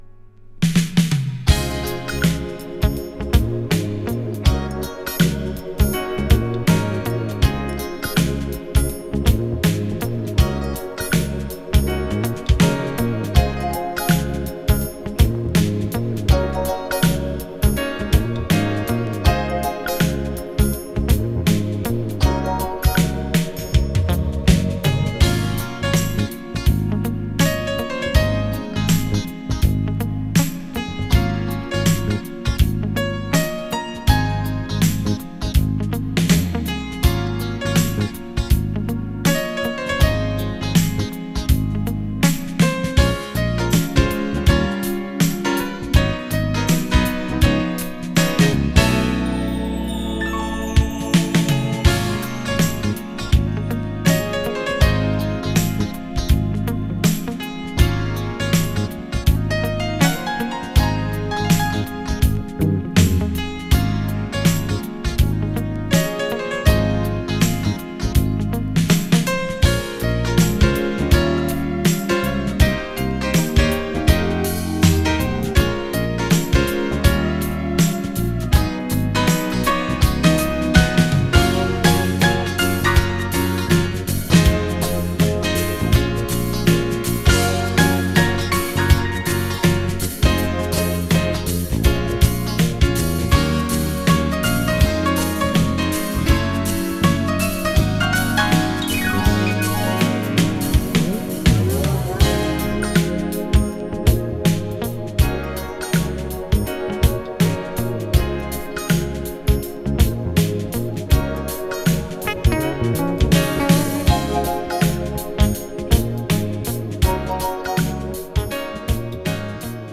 美しいソロピアノから始まる